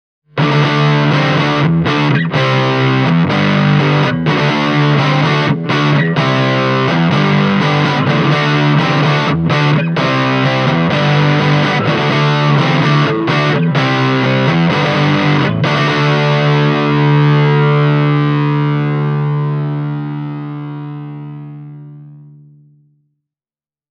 A nice side effect of going oh-point-one Watts is that it also adds another wallop of overdrive into the mix, which is why you can even get some decent Hard ’n’ Heavy-tones out of this tiny rig.
Here’s a taste of what I got at 0.1 Watts and Loudness turned all the way up.
marshall-offset-e28093-junior-drive.mp3